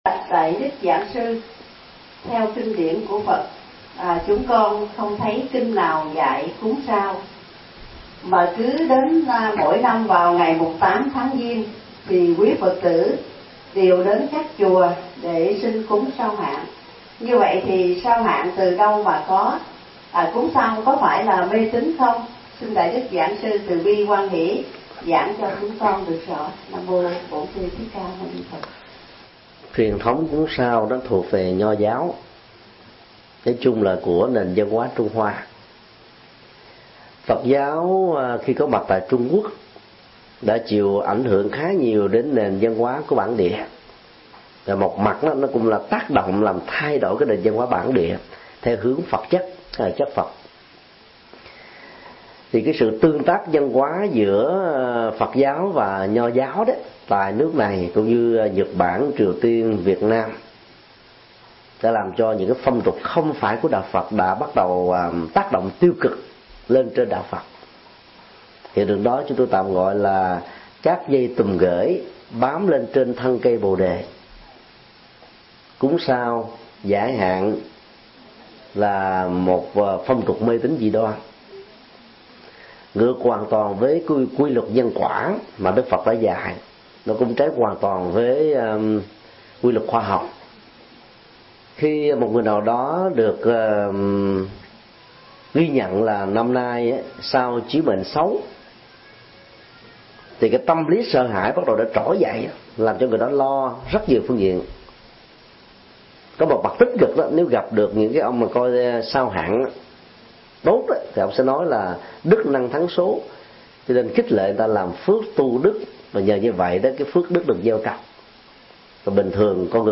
Vấn đáp: Phong tục cúng sao, giải hạn